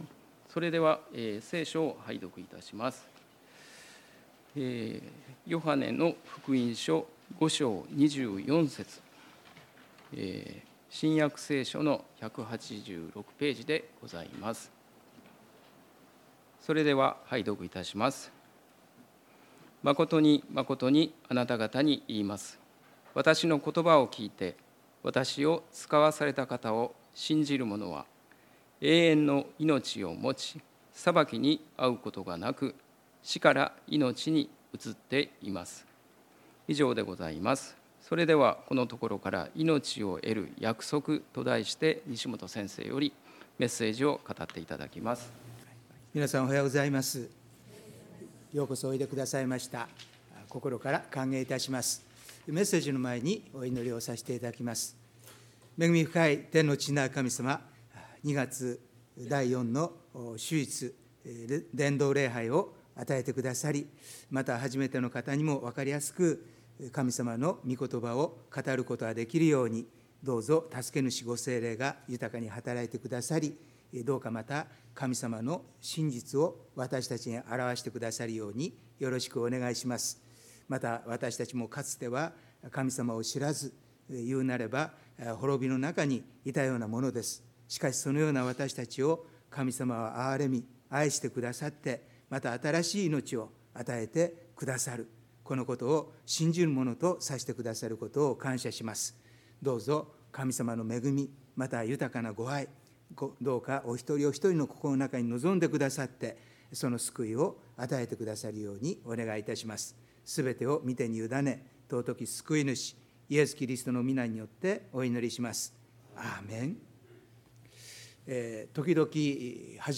礼拝メッセージ「いのちを得る約束」│日本イエス・キリスト教団 柏 原 教 会